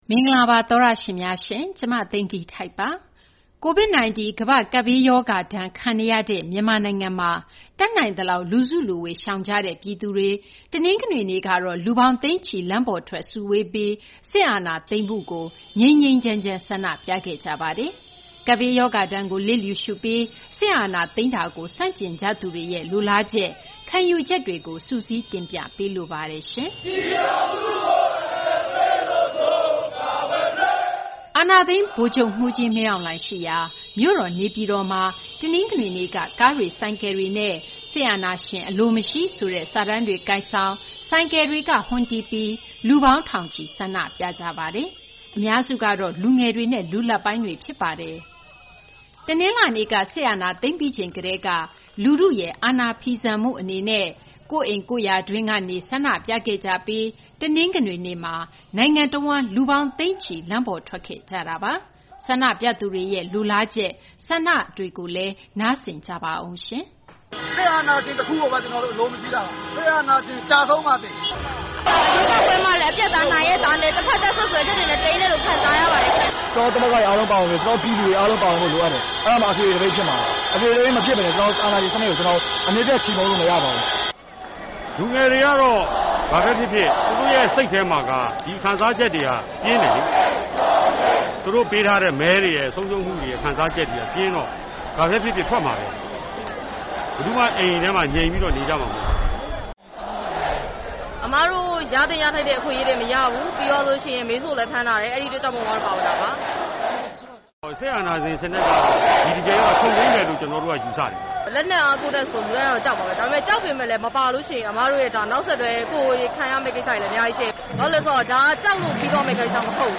ဆန္ဒပြသူတို့ရဲ့ ရင်ထဲက စကားသံများ
အာဏာသိမ်း ဗိုလ်ချုပ်မှူးကြီး မင်းအောင်လှိုင် ရှိရာ မြို့တော် နေပြည်တော်မှာ တနင်္ဂနွေနေ့က ကားတွေ ဆိုင်ကယ်တွေနဲ့ စစ်အာဏာရှင် မလိုမရှိဆိုတဲ့ စာတန်းတွေ ကိုင်ဆောင်၊ ဆိုင်ကယ်တွေက ဟွန်းတီးပြီး လူပေါင်း ထောင်ချီ ဆန္ဒပြခဲ့ကြတာပါ။